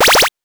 powerup_11.wav